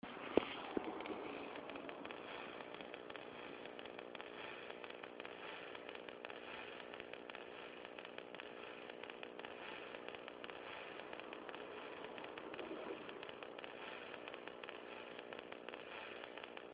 It starts to click randomly after the first few minutes of being turned on out of standby. Then it progressivly gets worse until it goes into a continuous CLICK CLICK CLICK_______CLICK CLICK CLICK________CLICK CLICK CLICK (underscore represents a 1 second silence). It doesn't get louder when volume is turned up and stops when in standby mode.
I'm putting up a short sound clip of the noise .